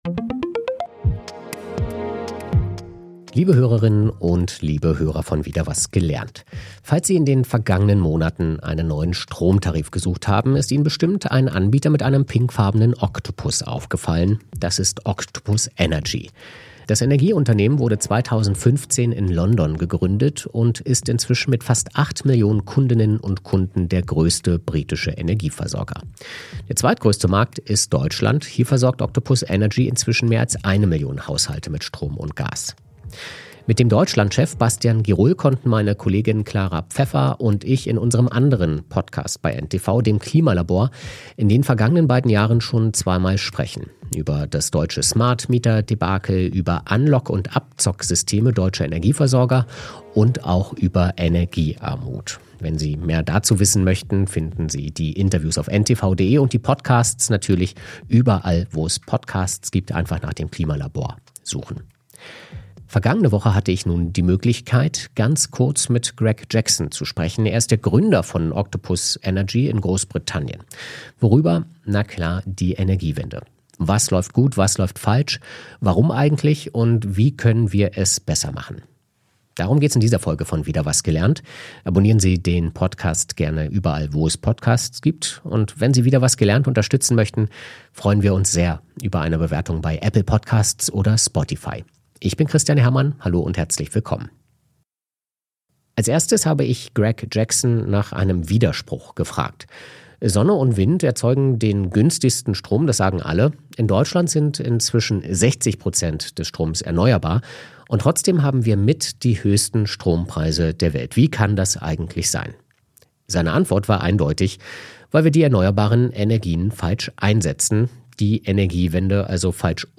Der Podcast analysiert, warum Deutschland trotz eines hohen Anteils erneuerbarer Energien zu den Ländern mit den höchsten Strompreisen zählt. Laut dem Interviewpartner liegt dies an einer fehlerhaften Umsetzung der Energiewende, die moderne Technologien und flexible Verbrauchskonzepte unzureichend integriert.
• Der Interviewpartner sieht den Hauptgrund für die Probleme der Energiewende im Einfluss von Lobbyisten fossiler Industrien, die politisch teure und ineffiziente Lösungen forcieren, ähnlich der Blockadehaltung etablierter Medien gegenüber dem Internet.